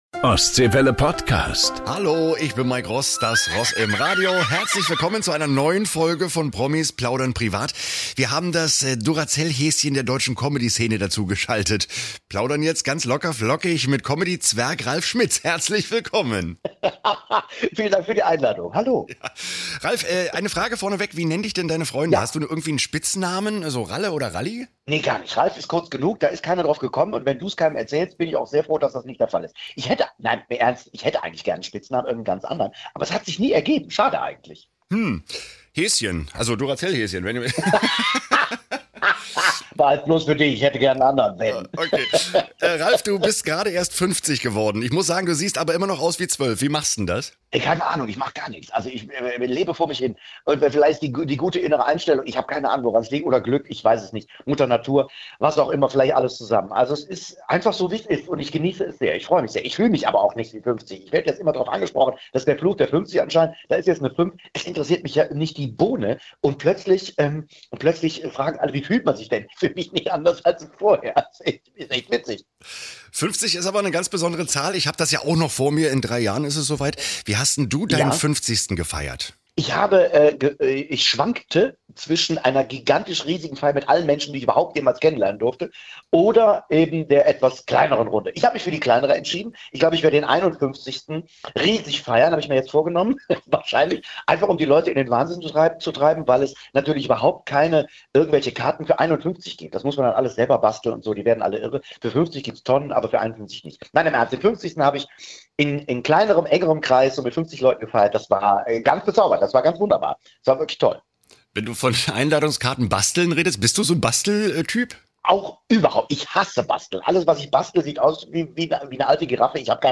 Beschreibung vor 1 Jahr Er ist der Tausendsassa der deutschen Comedy-Szene: Komiker, Musiker, Moderator, Schauspieler, Synchronsprecher und Autor.